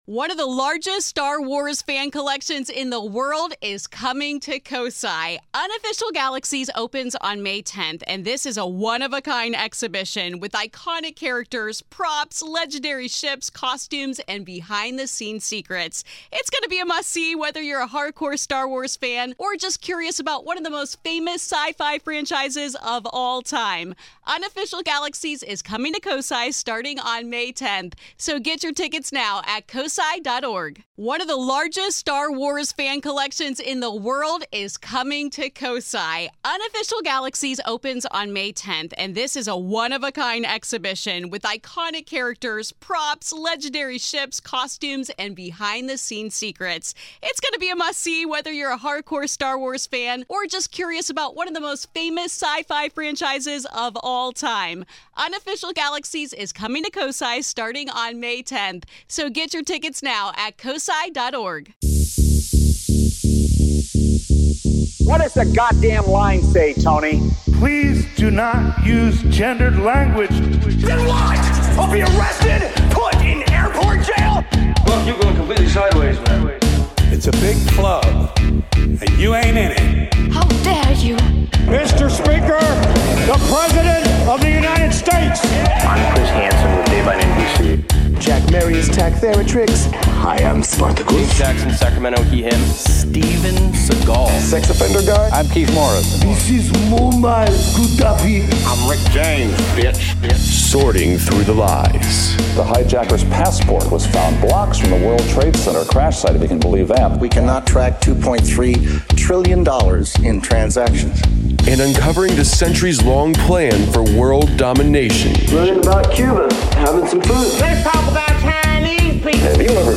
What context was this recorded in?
By the way, the audio quality gets better as the episodes go on.